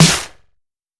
Snare (8).wav